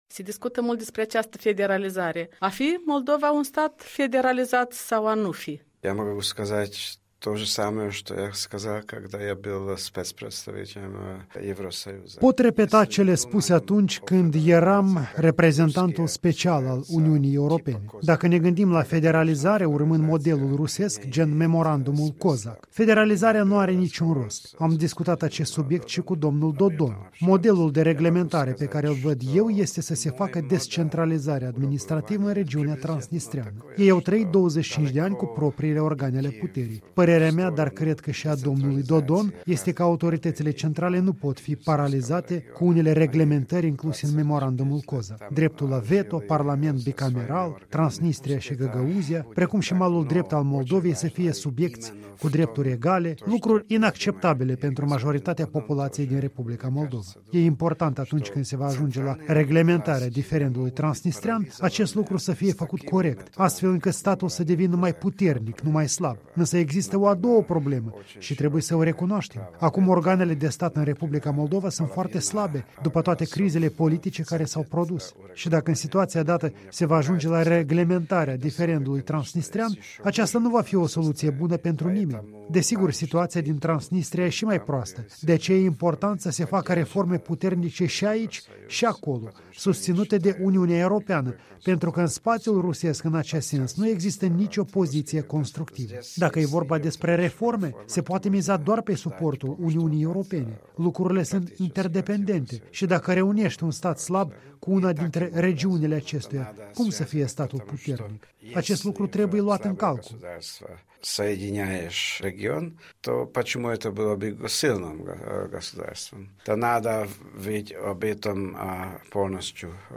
Un interviu cu fostul reprezentant special al Uniunii Europene în Republica Moldova.